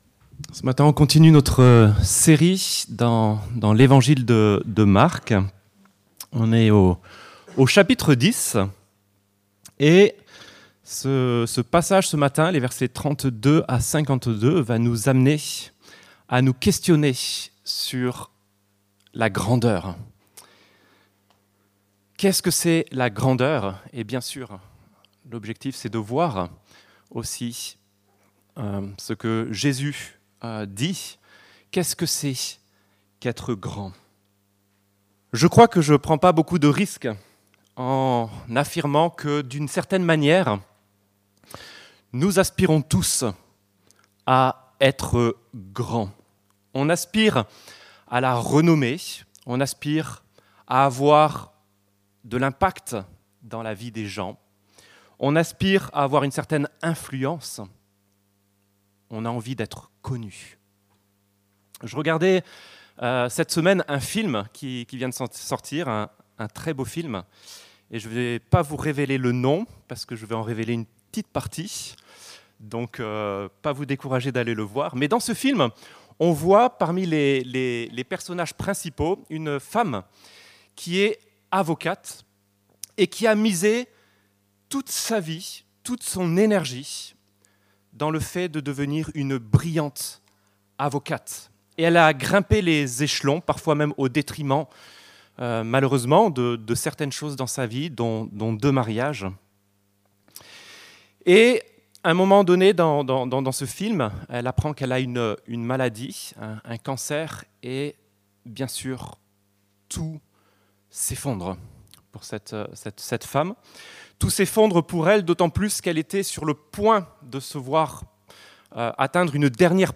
La vraie grandeur selon Jésus - Prédication de l'Eglise Protestante Evangélique de Crest sur l'Evangile de Marc